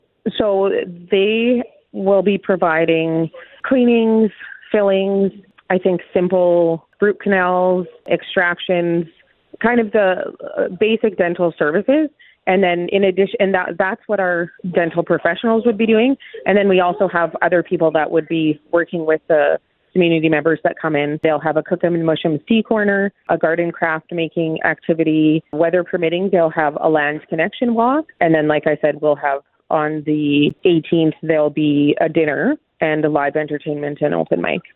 Alook speaks on the services that the free dental clinic will provide to MNA citizens that applied for the services, such as cleanings, fillings and extractions.